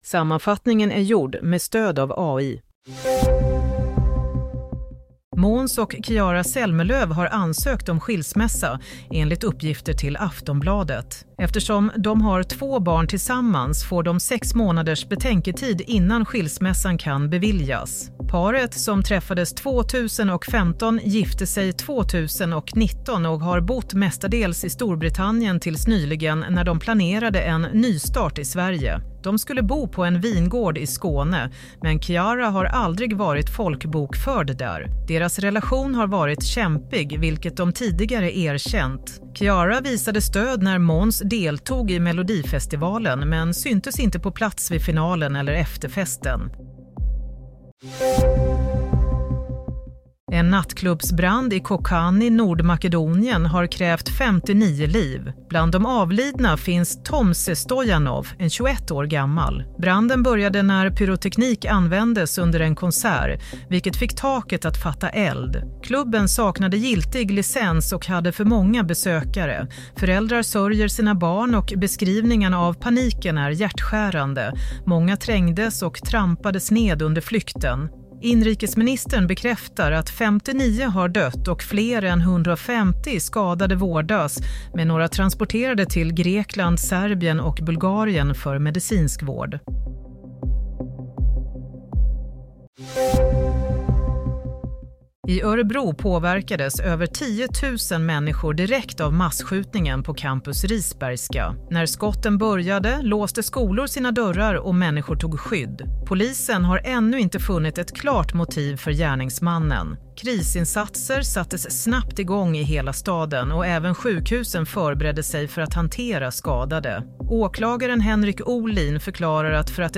Nyhetssammanfattning - 17 mars 16:00